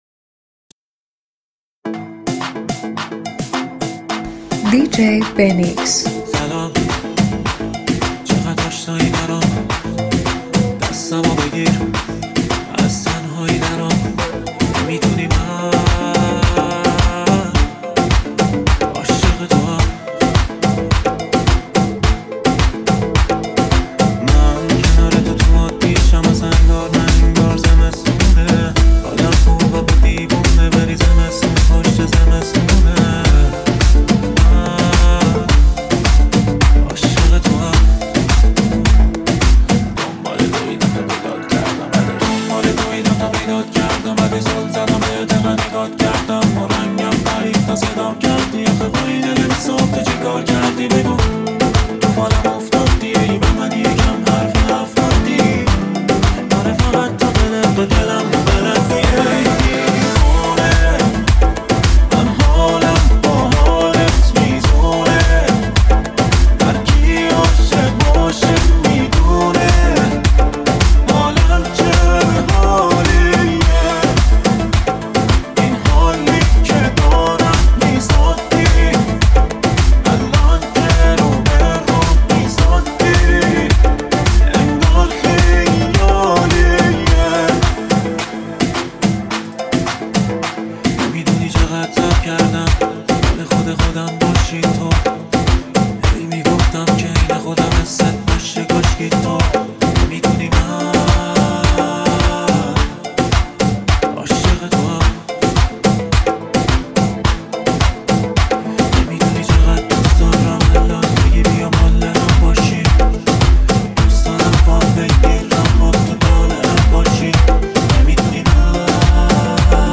یه ریمیکس شاد، پرانرژی و پر از هیجان